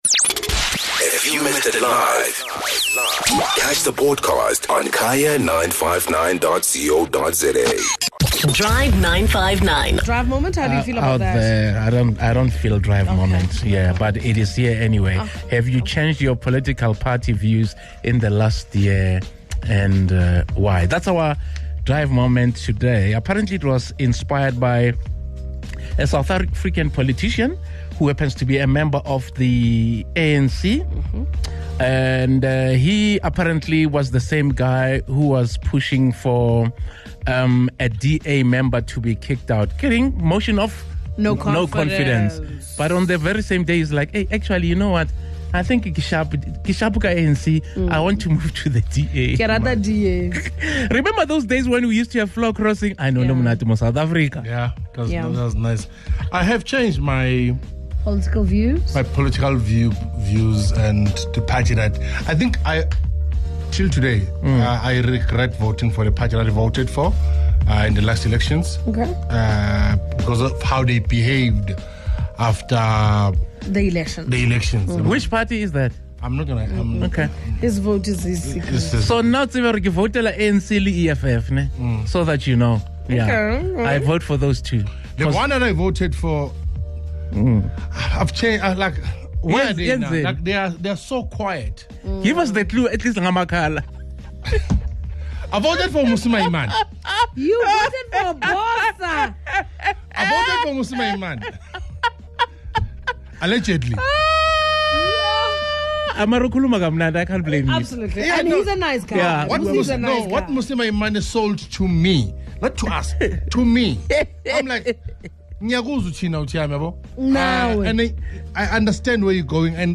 Let's hear what the Drive 959 listeners and anchors had to say!